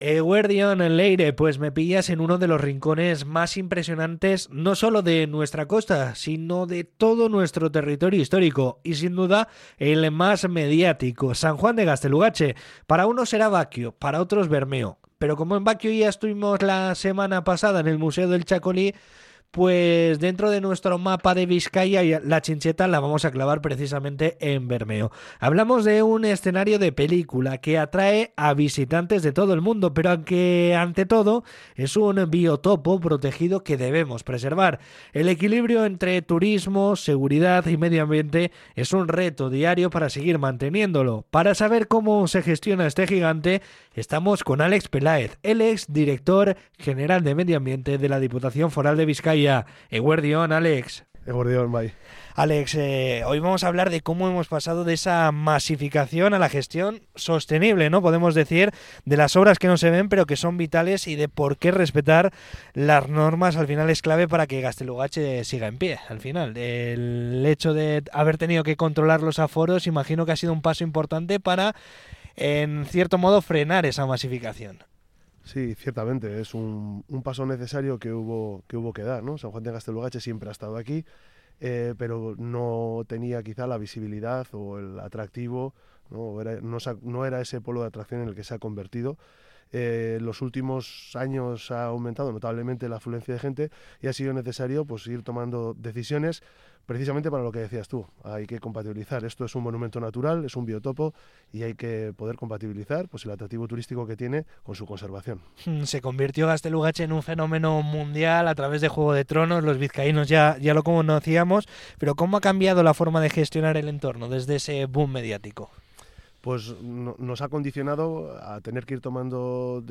Entrevista con el Director General de Medio Ambiente de la Diputación Foral de Bizkaia, Alex Pelaez